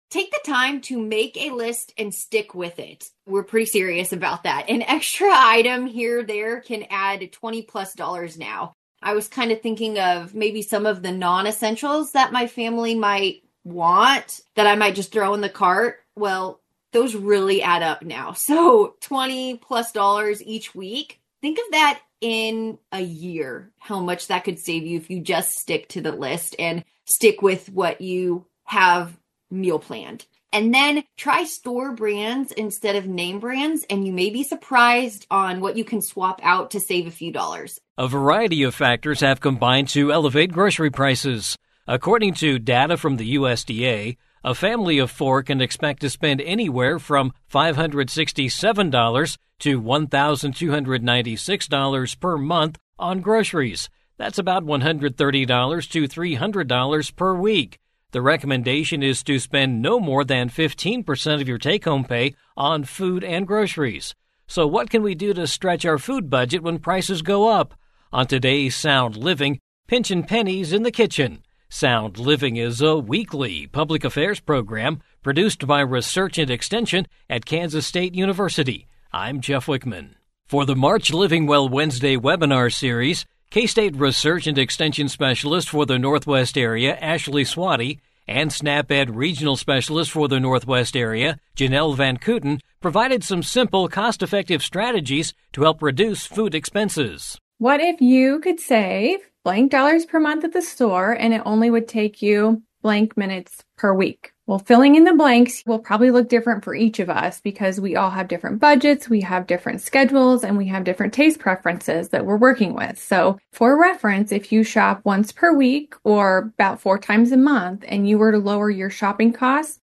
Sound Living is a weekly public affairs program addressing issues related to families and consumers.